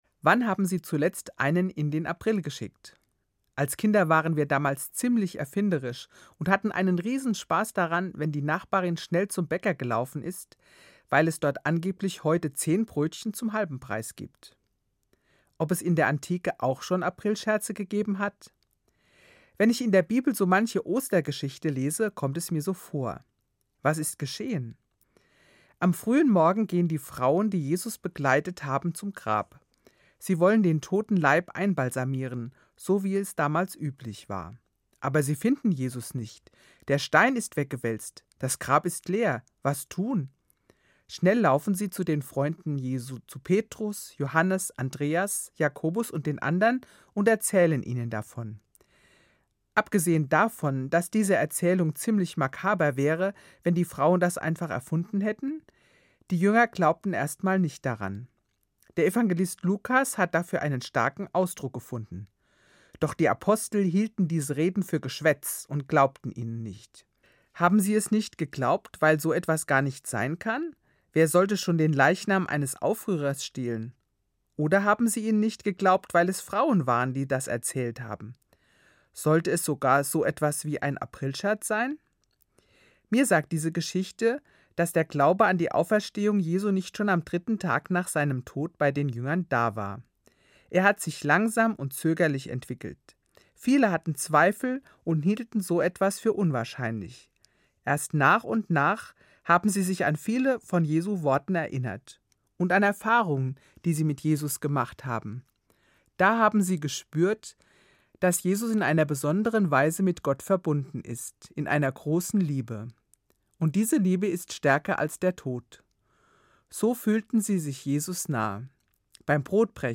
Katholische Gemeindereferentin im Ruhestand